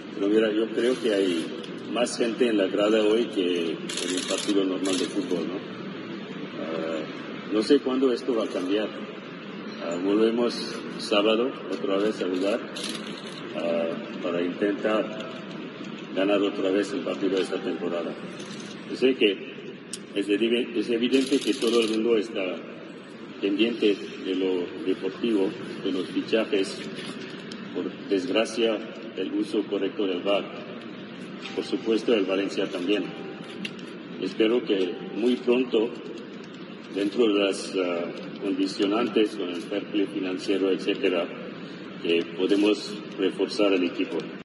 El presidente del Valencia CF lanza un mensaje de tranquilidad a la afición, en la presentación de un acto a favor de la Fallas 2021